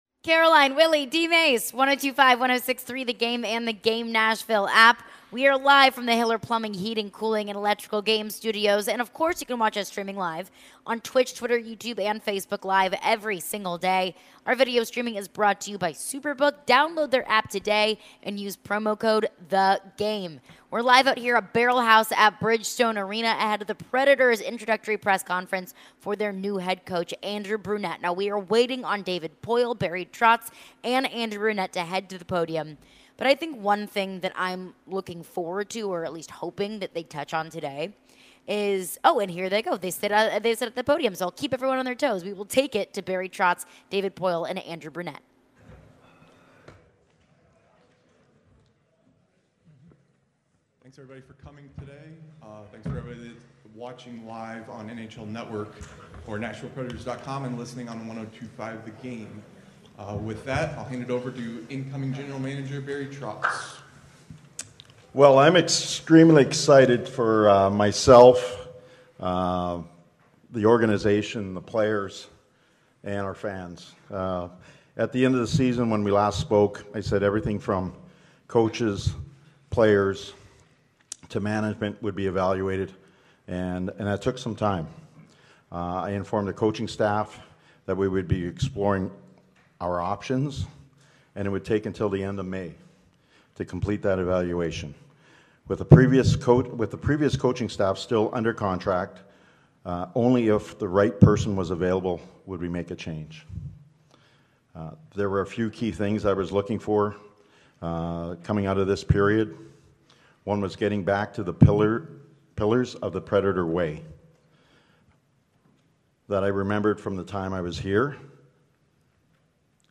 an introductory presser